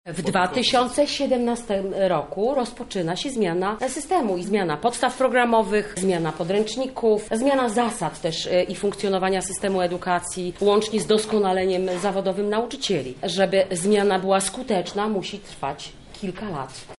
Trwa XV Ogólnopolska Konferencja Nauczycieli i Wychowawców, zorganizowana przez Katedrę Pedagogiki KUL.